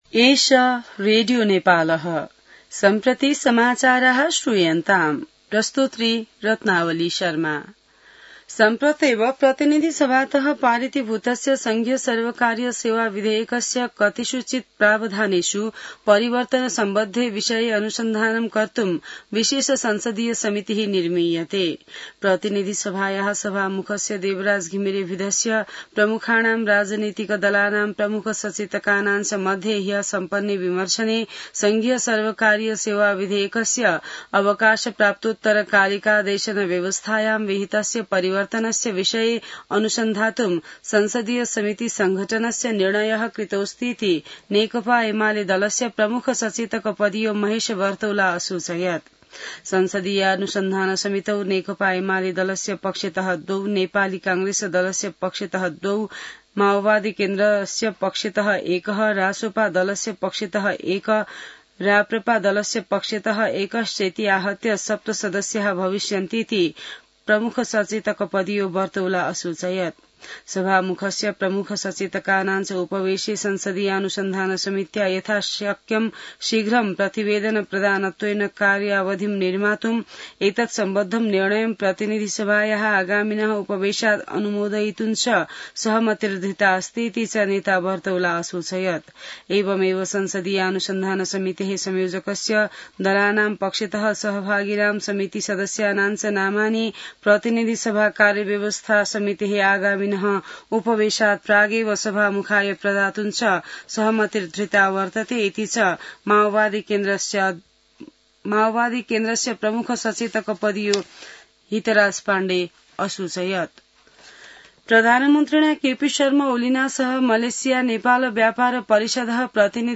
संस्कृत समाचार : २२ असार , २०८२